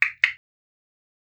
claquement-7.wav